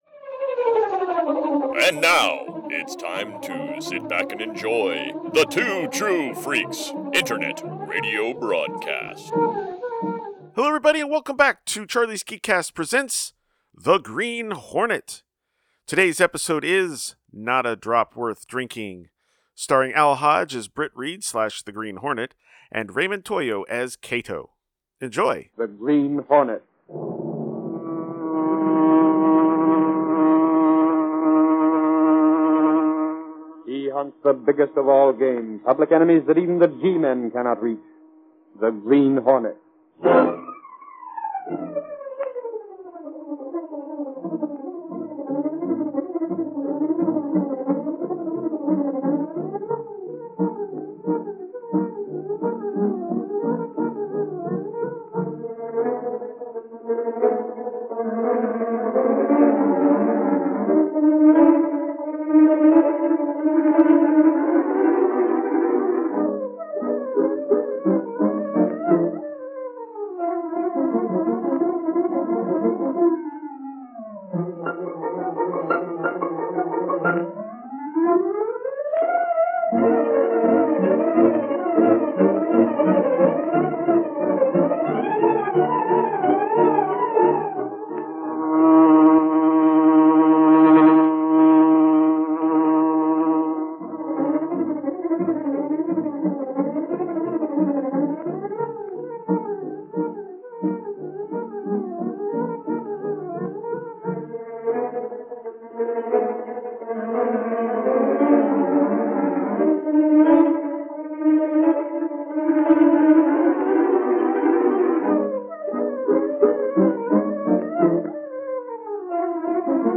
See author's posts Tagged as: Kato , The Daily Sentinel , The Black Beauty , radio series , The Green Hornet , Britt Reid . email Rate it 1 2 3 4 5